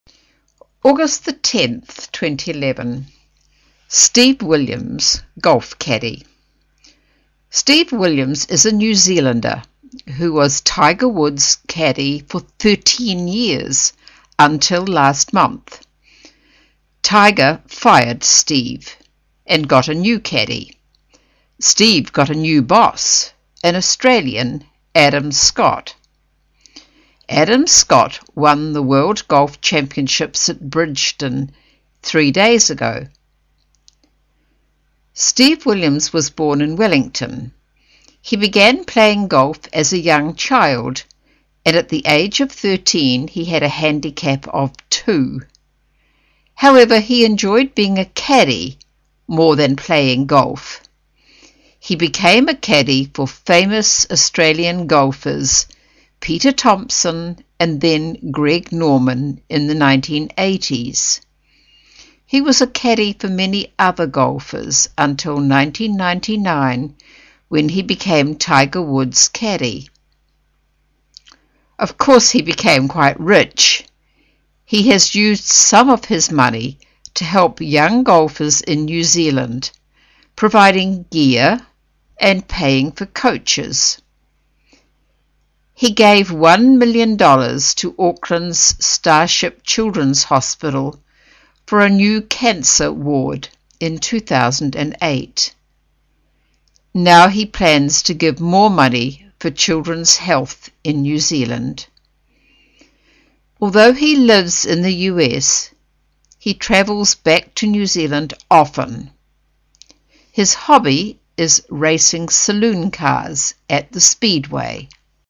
新西兰英语 477 Steve Williams, golf caddie 听力文件下载—在线英语听力室